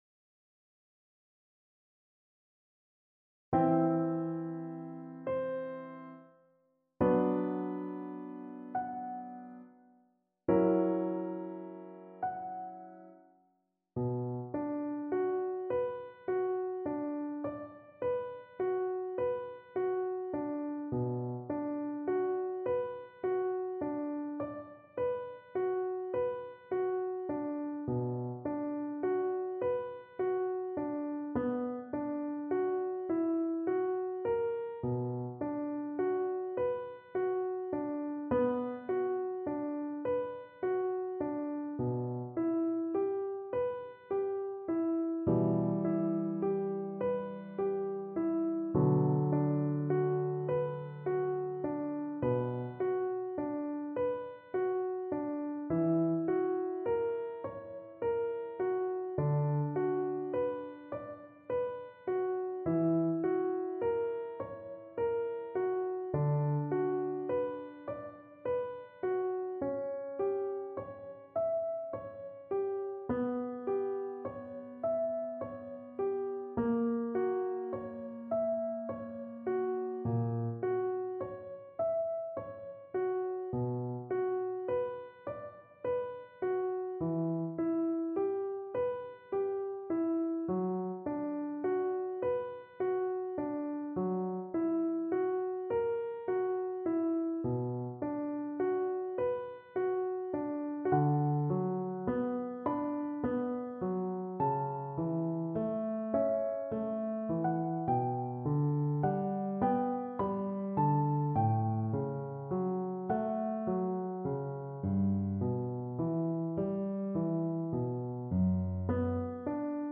Cello
D major (Sounding Pitch) (View more D major Music for Cello )
Andante cantabile =46
4/4 (View more 4/4 Music)
Classical (View more Classical Cello Music)